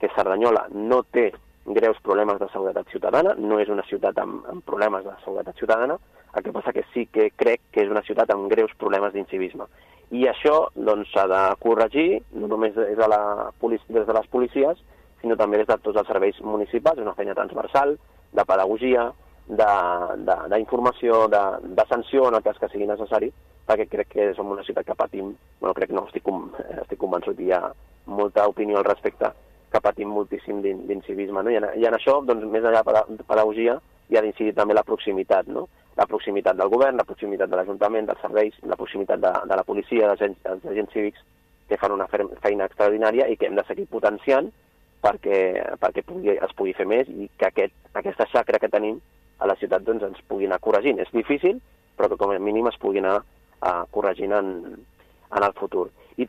Declaracions de Carlos Cordón: